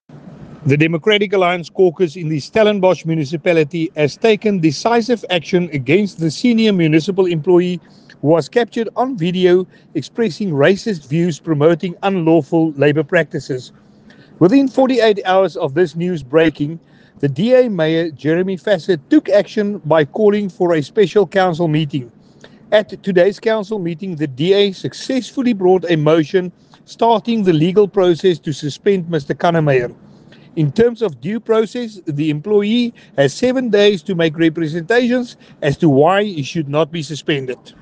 English soundbite by Willie Aucamp MP.